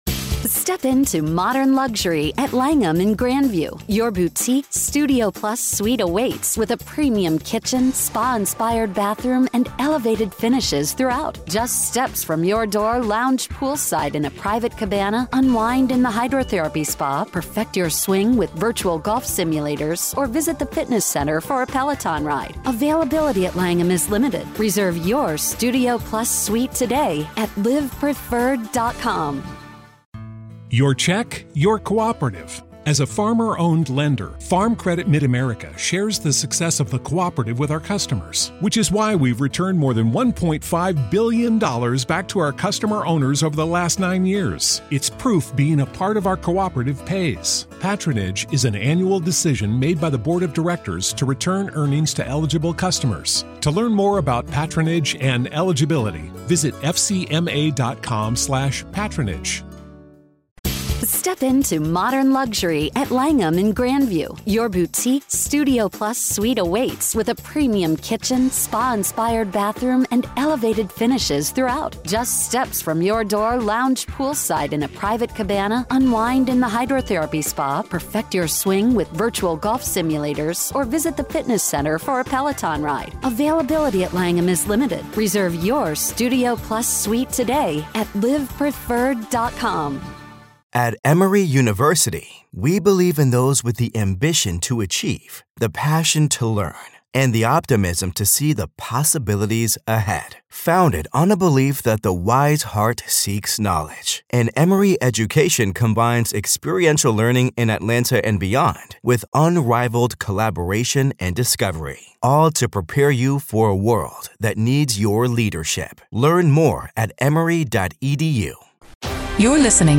Experience the raw courtroom drama firsthand as we delve into the "Rust" movie shooting trial with unfiltered audio and expert analysis.
Go beyond the headlines: Hear the emotional pleas and heated arguments directly from the courtroom. Gain insider insights from legal experts as they break down the complex charges, the defense strategy, and the potential impact on the industry.